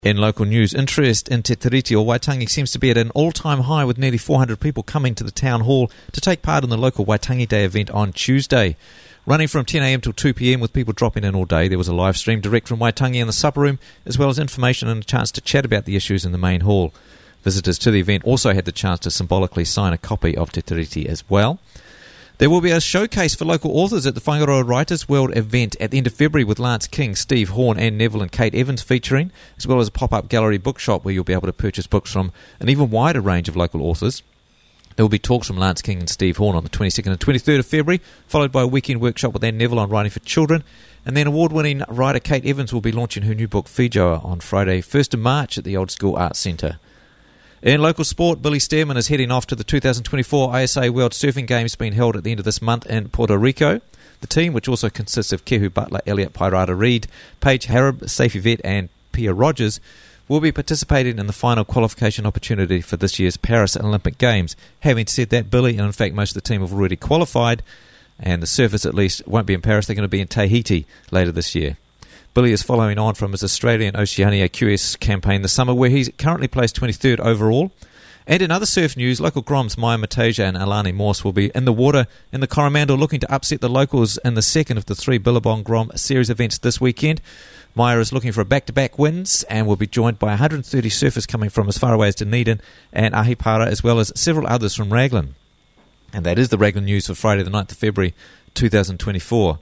Quickly and easily listen to Raglan News Bulletin for free!